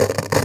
radio_tv_electronic_static_10.wav